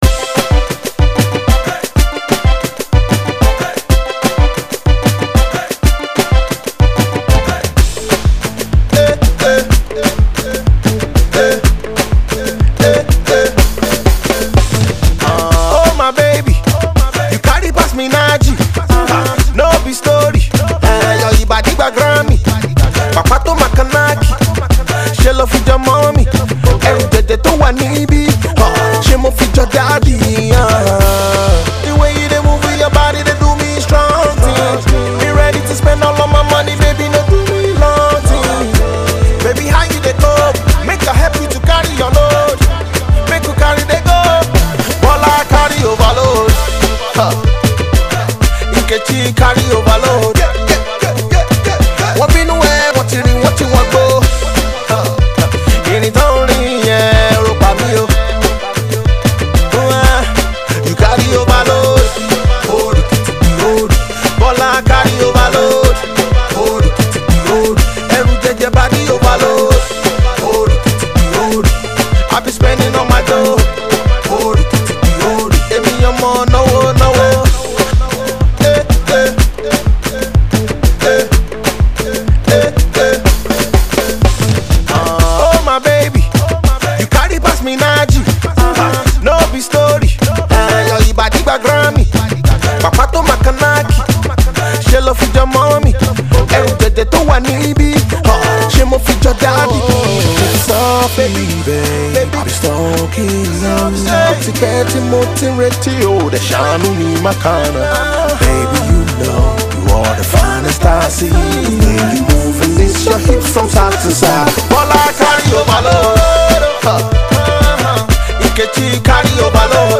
banger
percussion